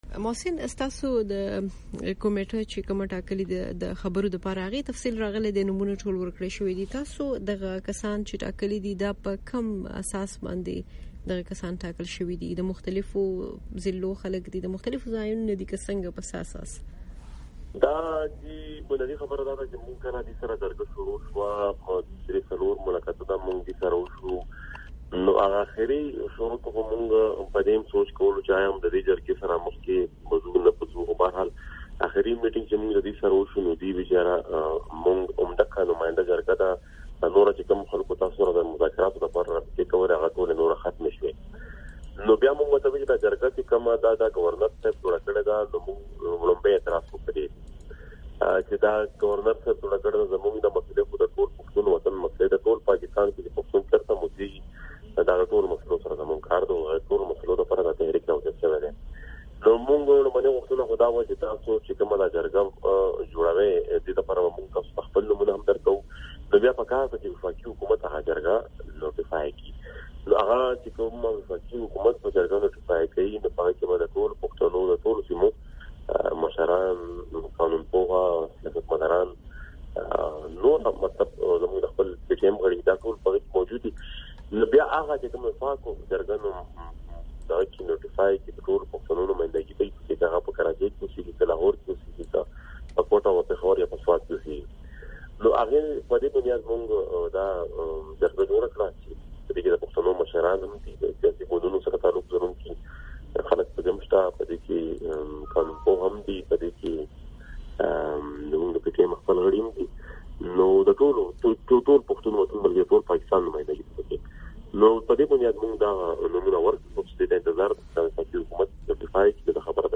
د محسن داوړ سره مرکه...